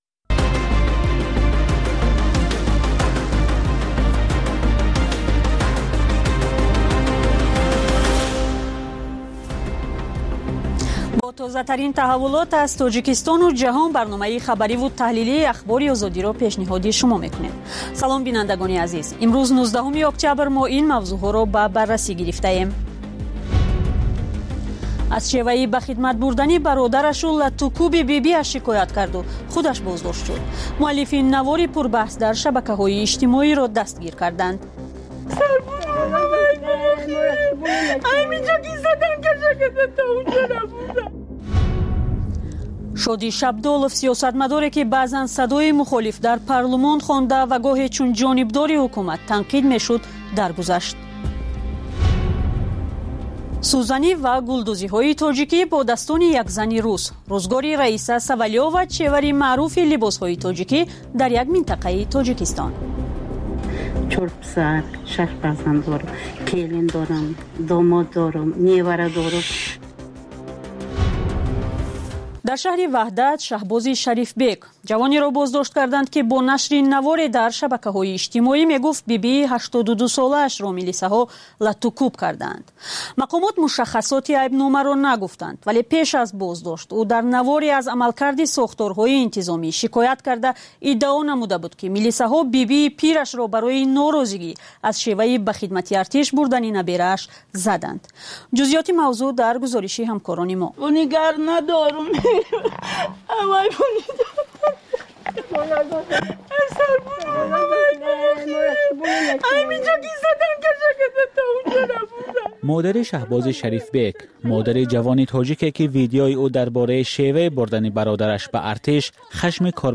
Фишурдаи охирин ахбори ҷаҳон, гузоришҳо аз Тоҷикистон, гуфтугӯ ва таҳлилҳо дар барномаи бомдодии Радиои Озодӣ.